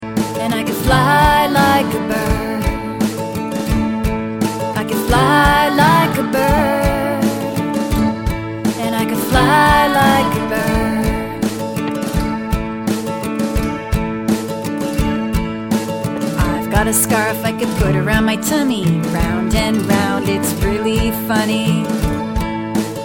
Children's Animal Song Lyrics and Sound Clip